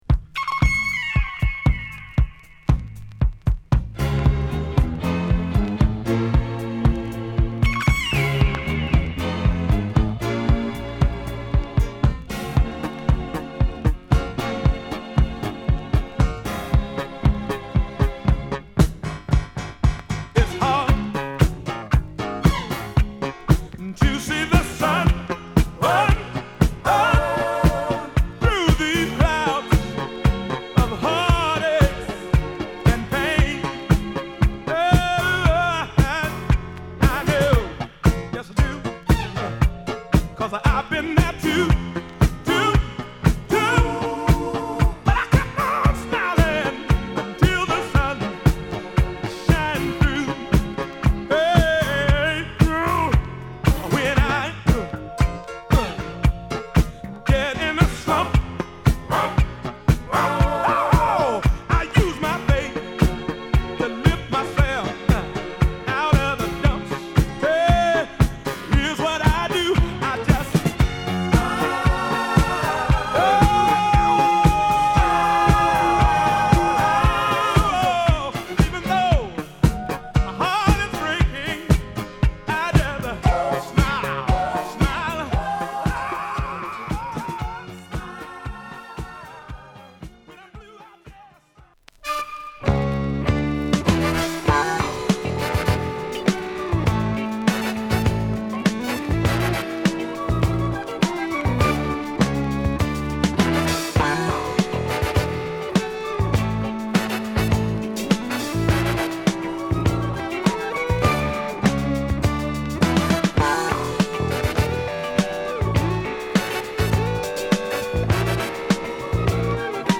タイトル通り笑顔が溢れるようなスマイリーなメロディラインが心地良い、ホッコリとしたゴスペル・ブギー！